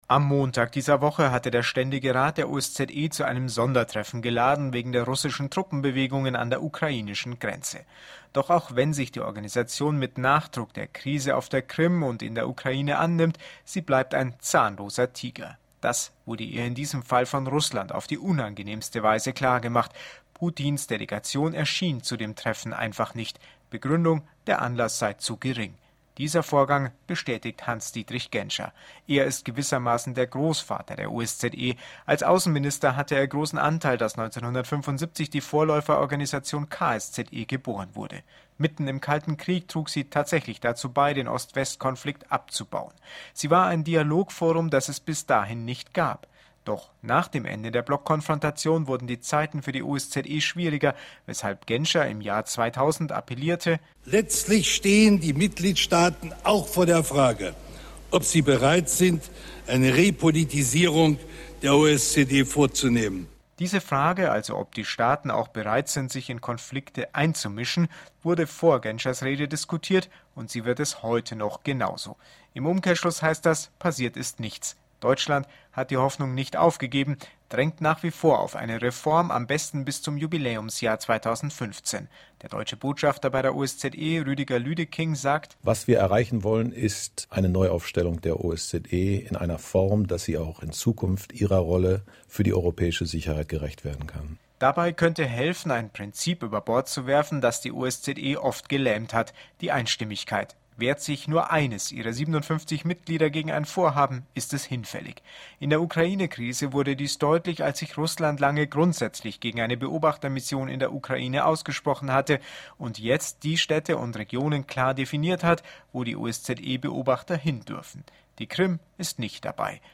„Zahnloser Tiger OSZE“ – Ein Audio-Beitrag